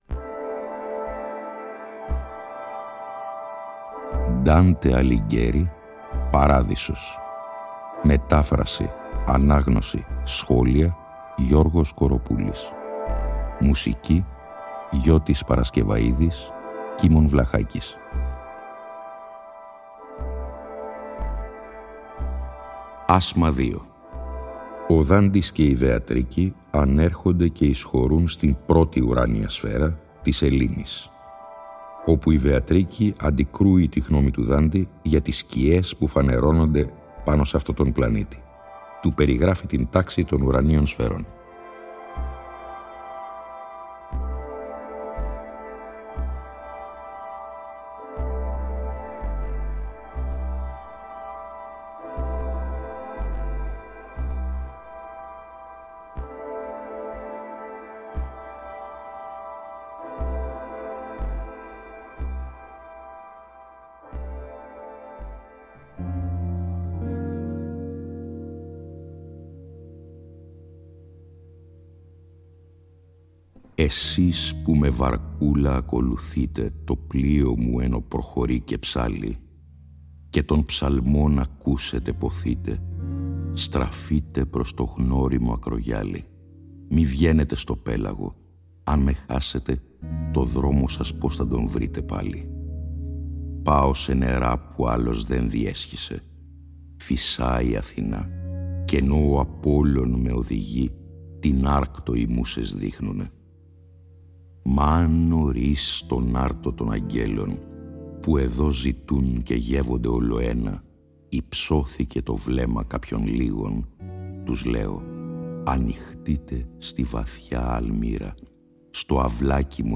Η ανάγνωση των 33 ασμάτων του «Παραδείσου», σε 20 ημίωρα επεισόδια, (συνέχεια της ανάγνωσης του «Καθαρτηρίου», που είχε προηγηθεί) συνυφαίνεται και πάλι με μουσική
Η μετάφραση τηρεί τον ενδεκασύλλαβο στίχο και υποτυπωδώς την terza rima του πρωτοτύπου – στο «περιεχόμενο» του οποίου παραμένει απολύτως πιστή.